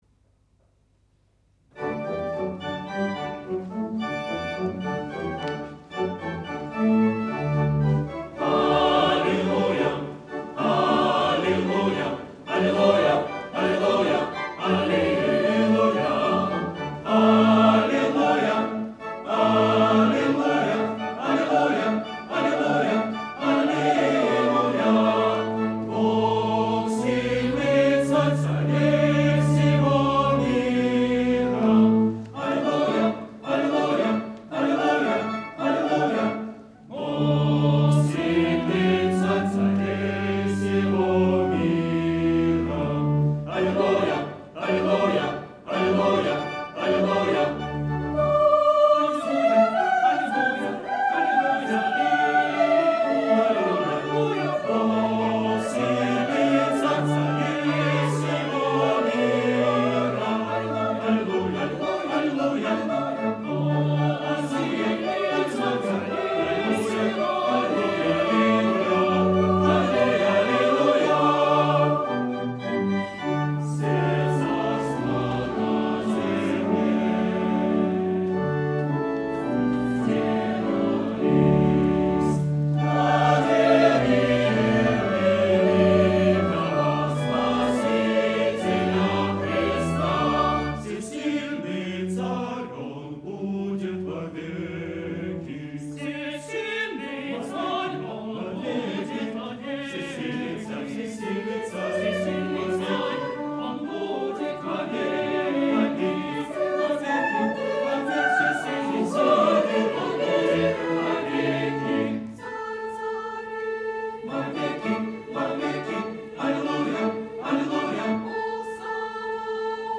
Рождественский вечер духовной музыки 7 января 2025